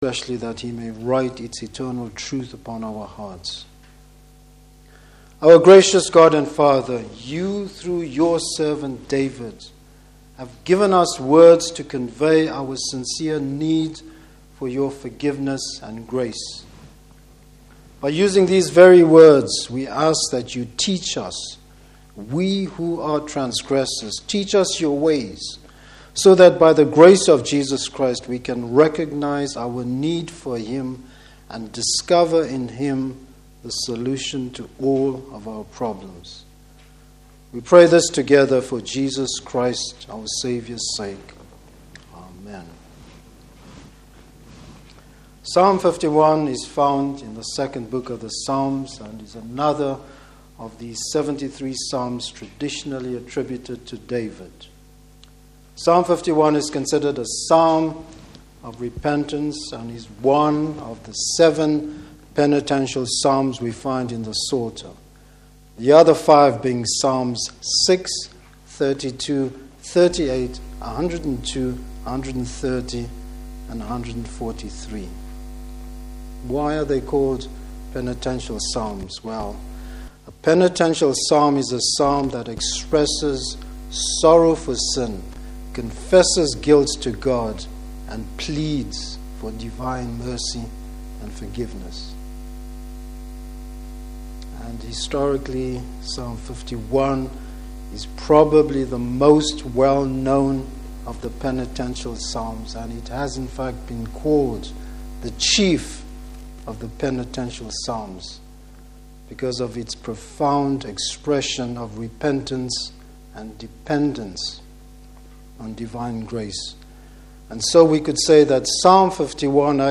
Service Type: Evening Service David throws himself on the Lord’s mercy.